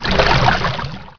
Here are some ripped soundFX from TR2 using Cool Edit Pro.